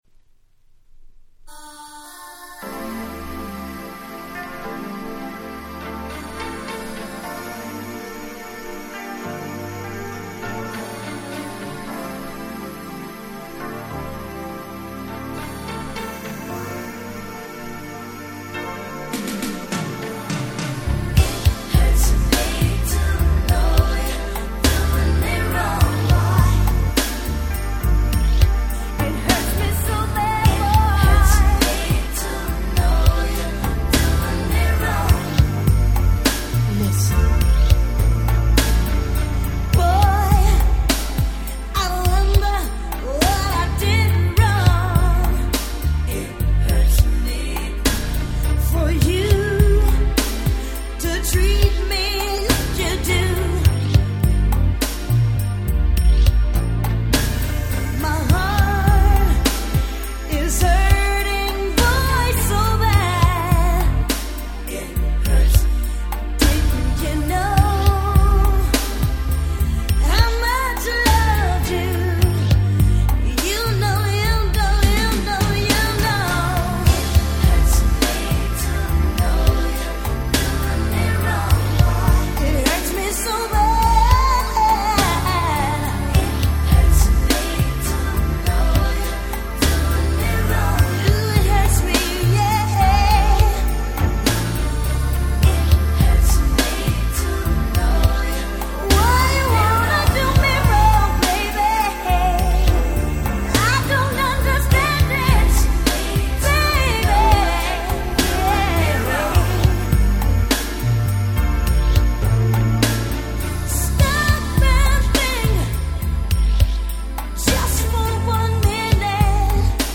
92' US Promo Only Remix !!
Sexyな珠玉のSlow Jamです!!!!!